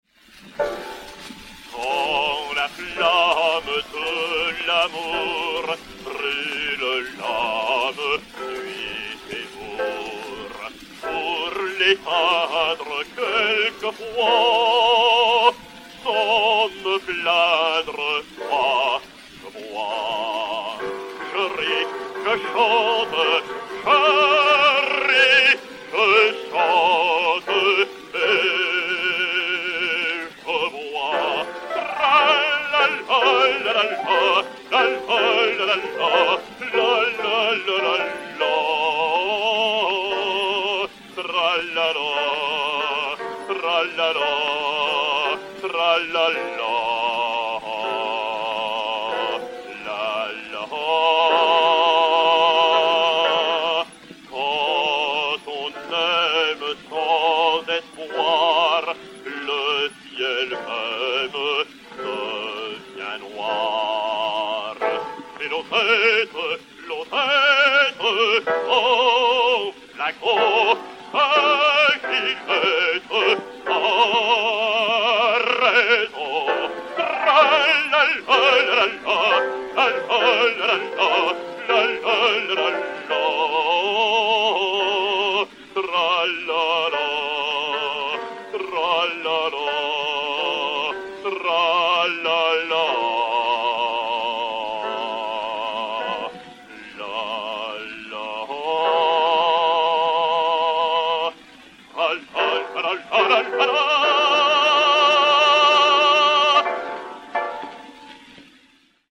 Air bachique "Quand la flamme de l'amour"
et Piano
Pathé saphir 90 tours n° 475, réédité sur 80 tours n° 79, enr. en 1904